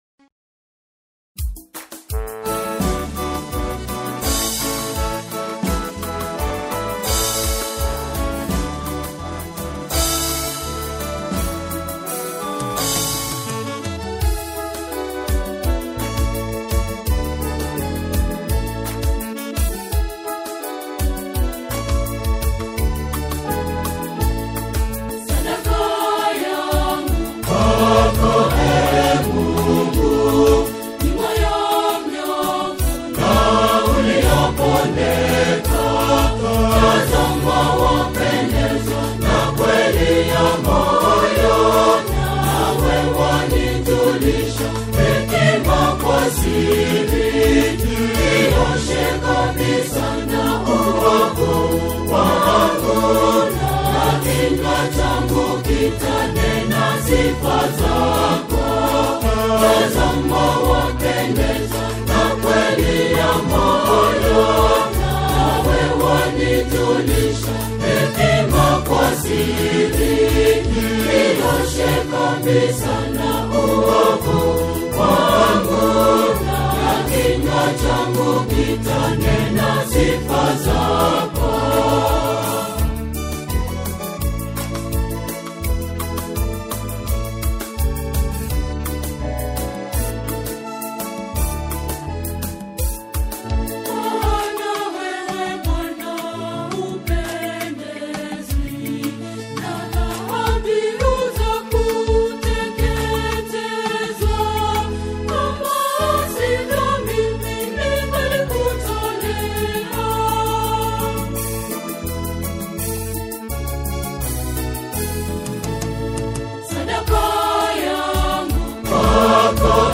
gospel tune
catholic choir